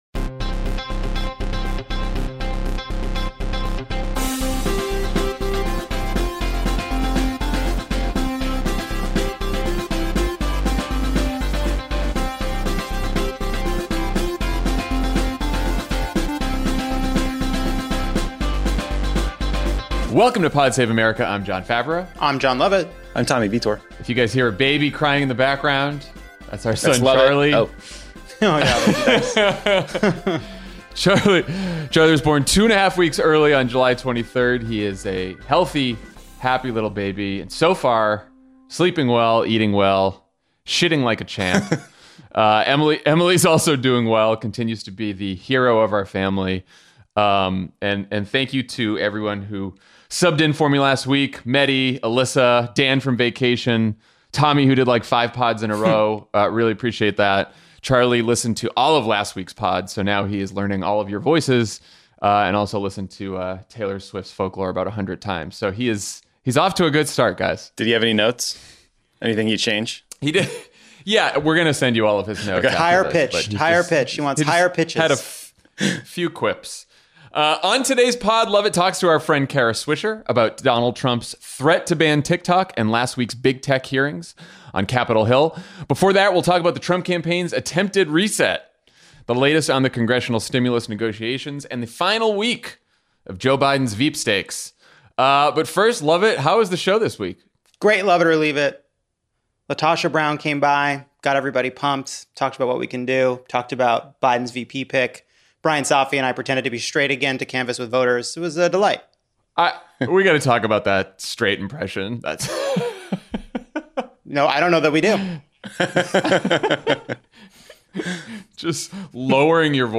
The Trump campaign smashes that reset button, the President tries to undermine the election by attacking mail-in voting and the Postal Service, Republicans refuse to extend unemployment benefits, and Joe Biden gets close to selecting his running mate. Then journalist Kara Swisher talks to Jon Lovett about Trump’s threat to ban TikTok and the tech CEOs who recently testified on Capitol Hill.